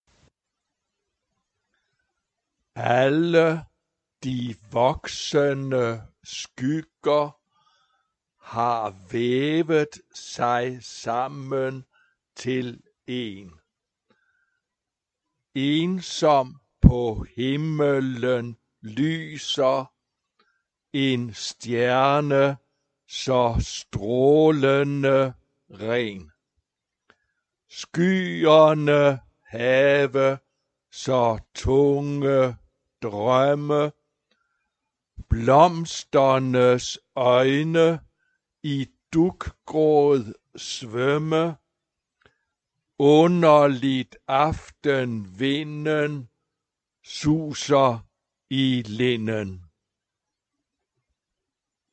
Género/Estilo/Forma: Profano ; Coro
Carácter de la pieza : poético ; efusivo
Tipo de formación coral: SATB  (4 voces Coro mixto )
Tonalidad : do menor
Palabras claves: estrella ; noche ; a cappella